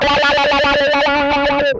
SITTING WAH1.wav